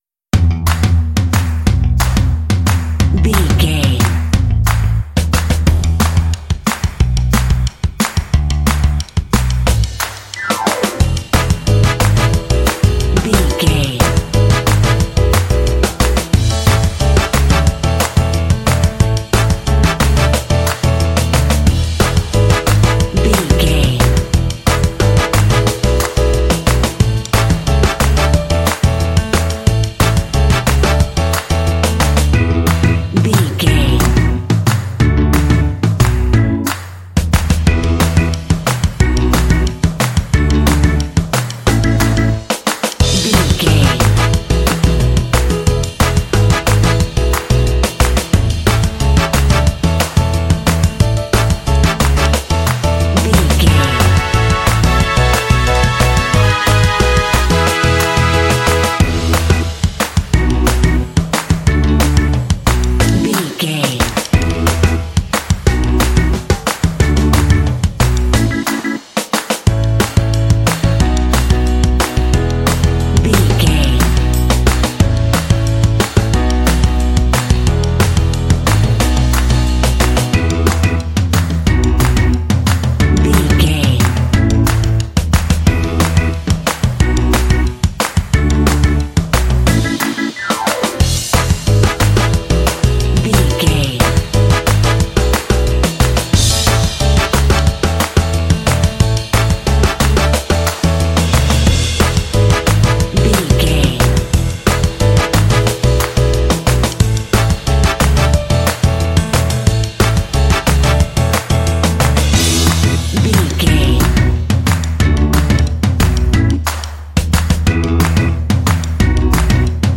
Ionian/Major
Fast
cheerful/happy
lively
energetic
playful
drums
bass guitar
piano
brass
electric organ
60s
70s
alternative rock